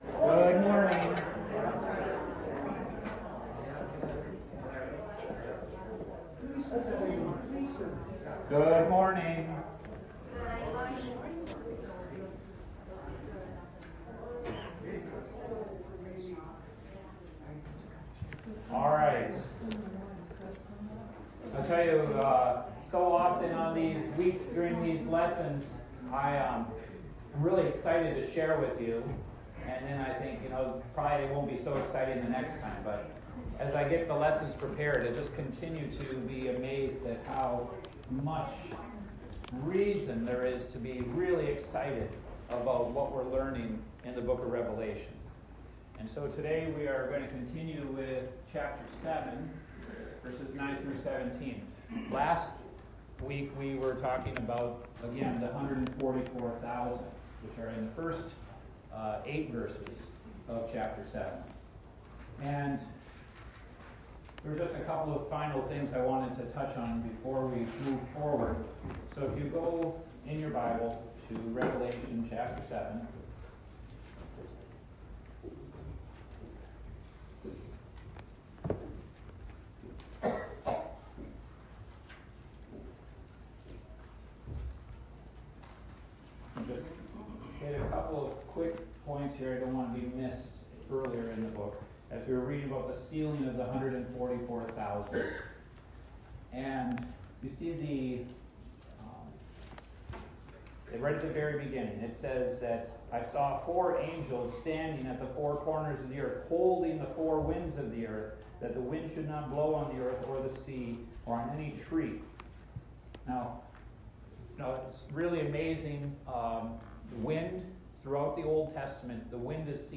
Adult Sunday School A Study in Revelation